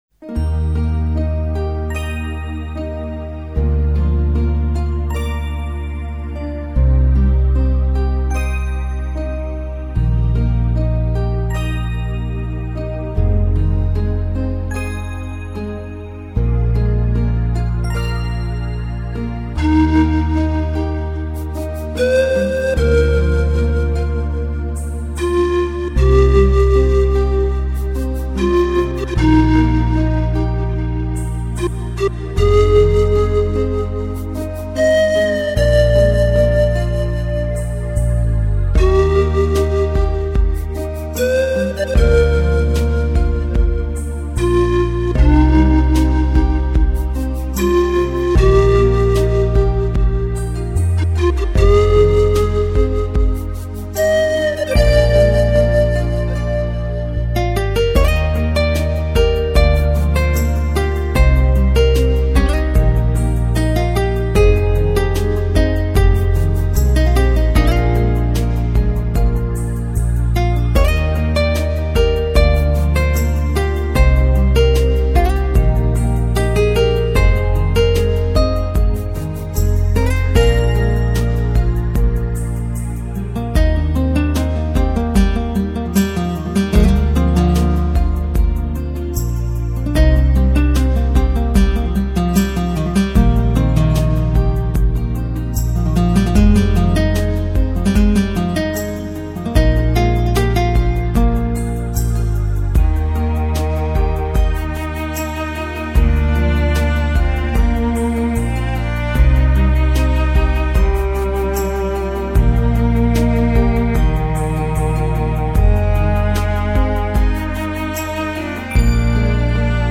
每一声虫鸣、流水，都是深入瑞士山林、湖泊， 走访瑞士的阿尔卑斯山、罗春湖畔、玫瑰峰山麓等地记录下来的。